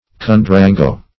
Search Result for " cundurango" : The Collaborative International Dictionary of English v.0.48: Cundurango \Cun`du*ran"go\ (k?n`d?-r?n"g?), n. (Med.)